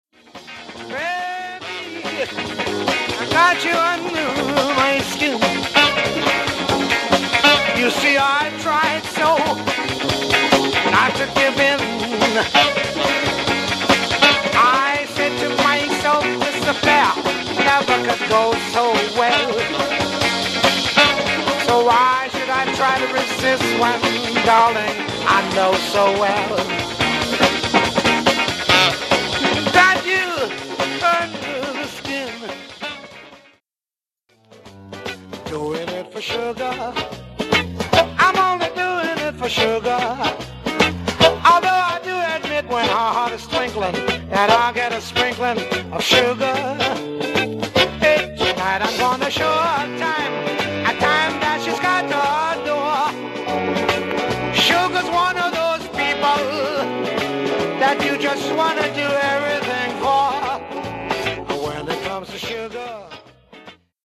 Genre: Jive/Swing/Jazz